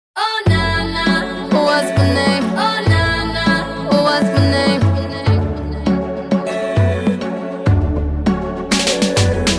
• Качество: 128, Stereo
женский голос
dance
спокойные
RnB